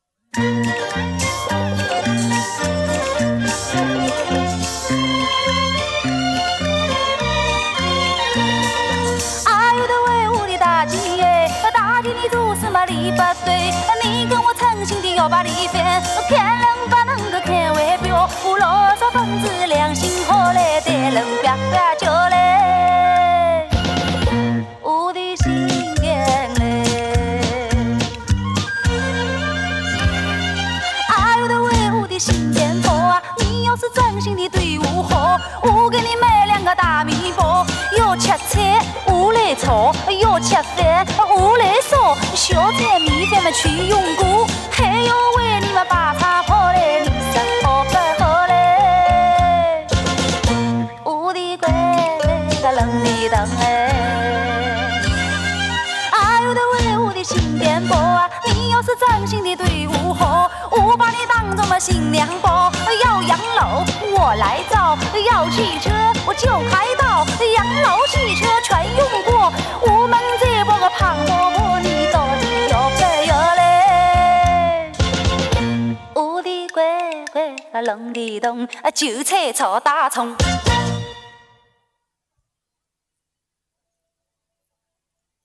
[21/5/2009]扬州小调--韭菜炒大葱 激动社区，陪你一起慢慢变老！